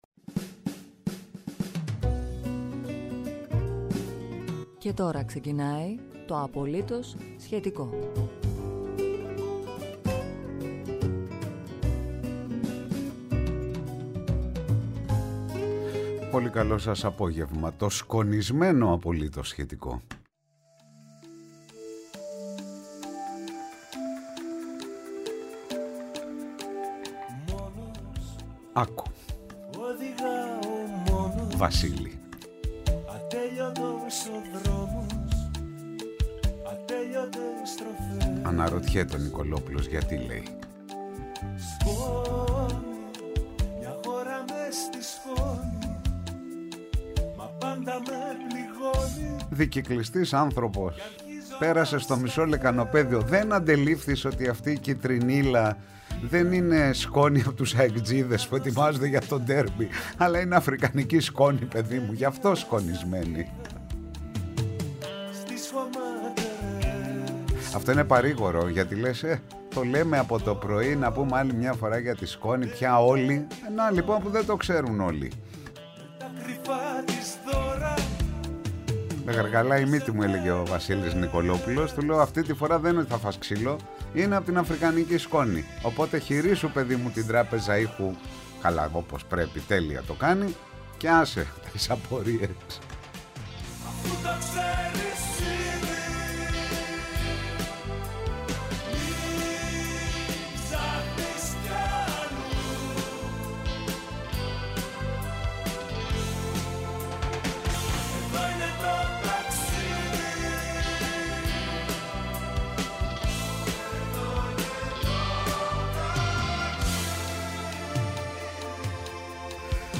ΠΡΩΤΟ ΠΡΟΓΡΑΜΜΑ